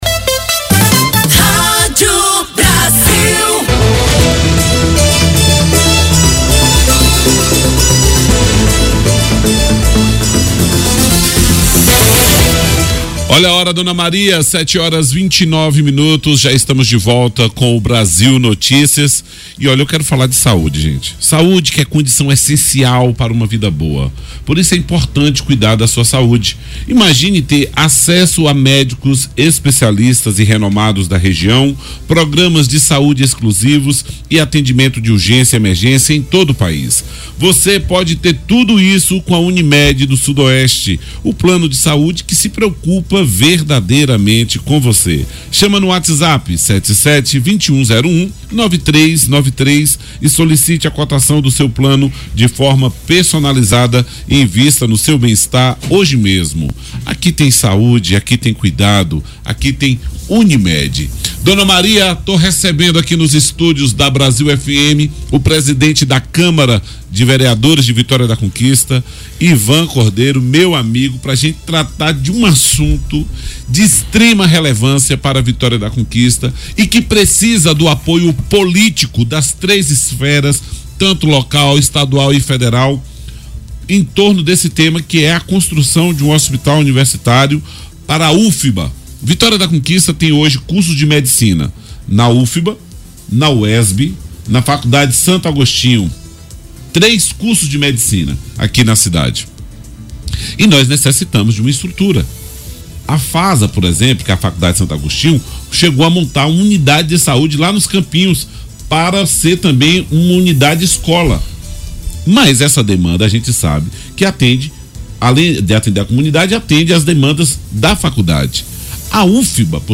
Em entrevista ao programa Brasil Notícias, o presidente da Câmara Municipal, Ivan Cordeiro, do PL, revelou que o investimento de 130 milhões de reais do novo PAC, que poderia vir para Conquista, foi destinado a Paulo Afonso, no Norte do estado.